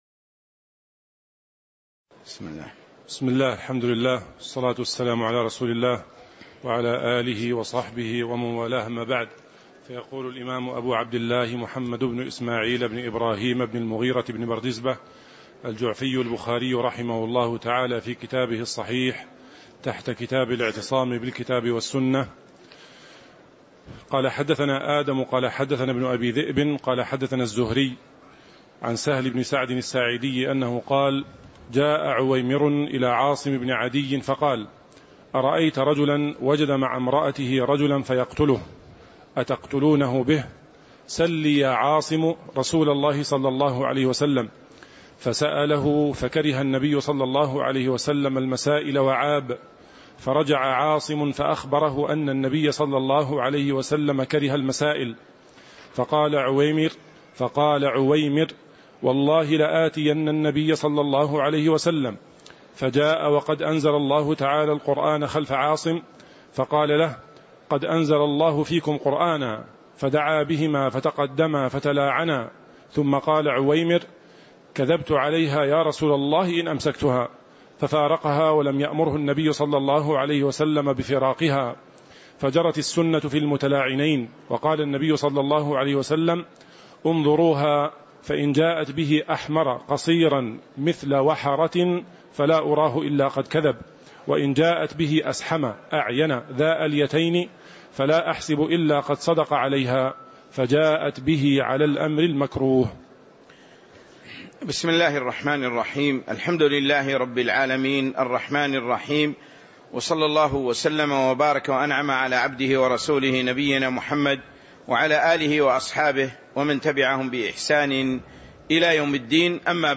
تاريخ النشر ٢١ جمادى الآخرة ١٤٤٦ هـ المكان: المسجد النبوي الشيخ